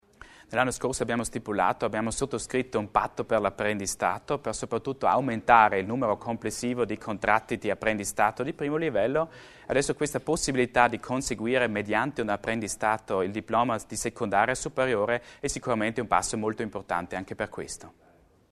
L'Assessore Achammer illustra le novità nell'apprendistato